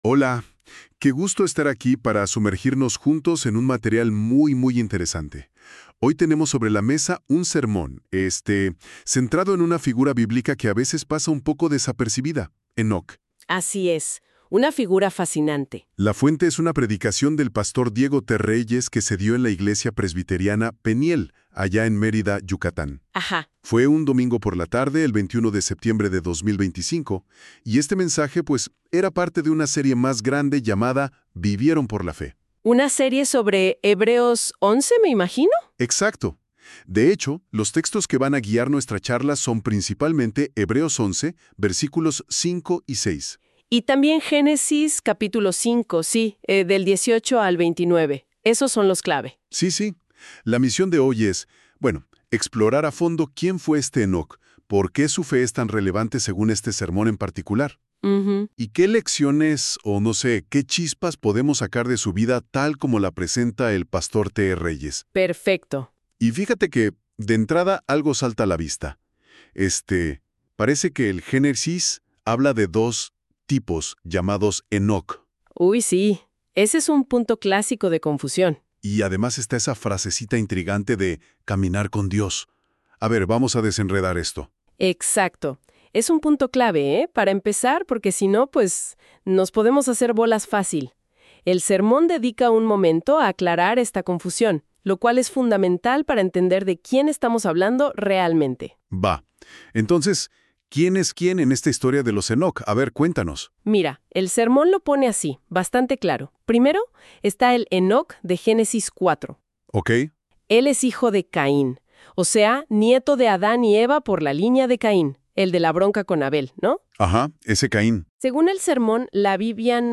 ENOC VIVIÓ POR LA FE – SERMONES